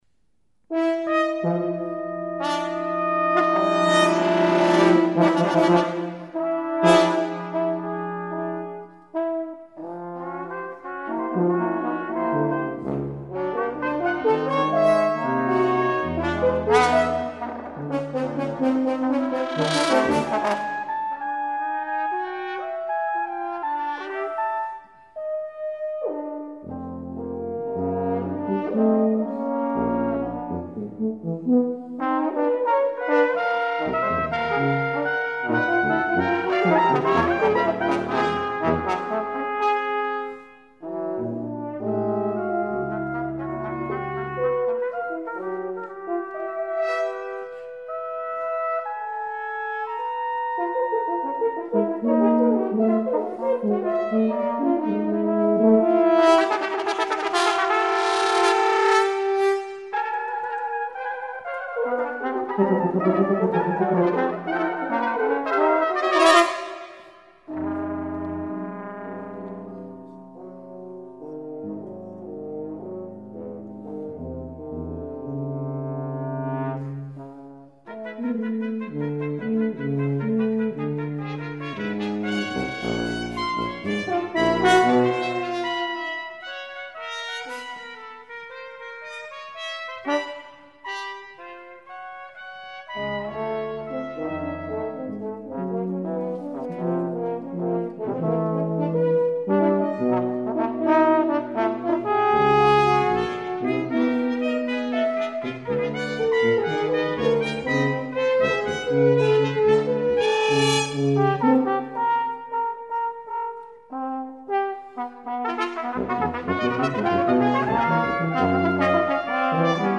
chamber composition